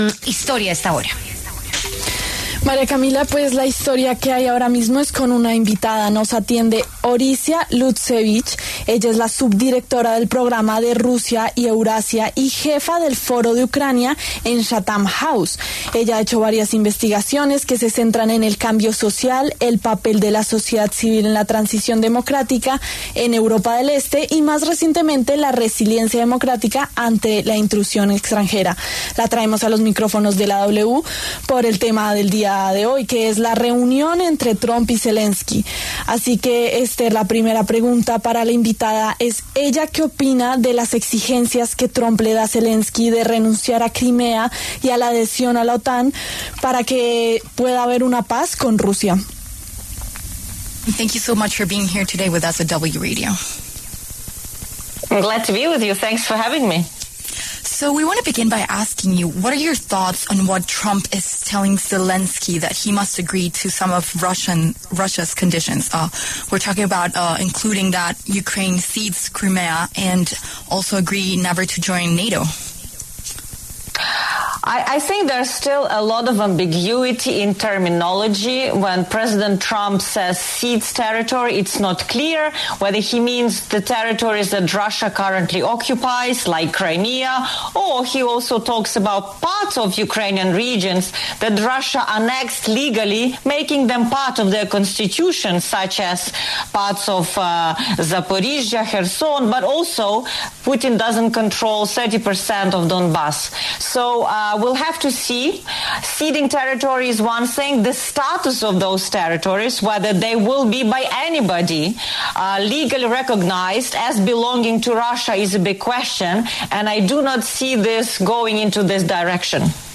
conversó con La W sobre la reunión entre Trump y Zelenski.